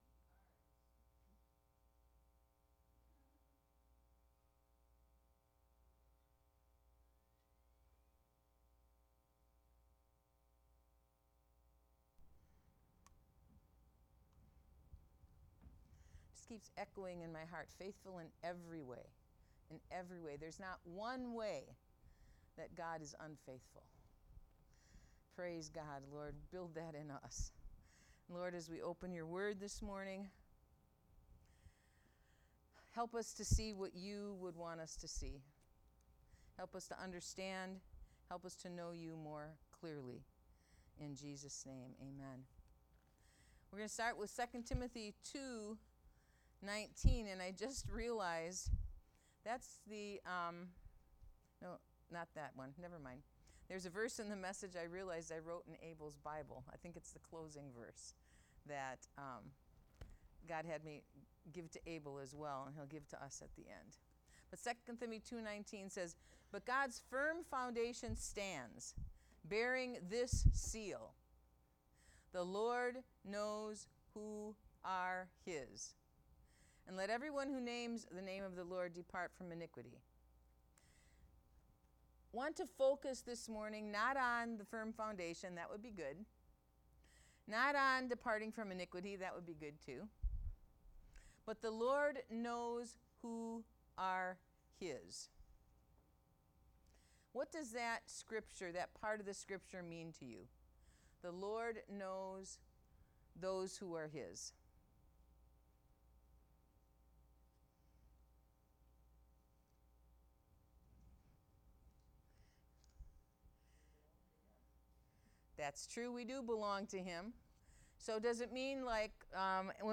Sermons | The City of Hope